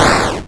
Add explosion sound effect
explosion.wav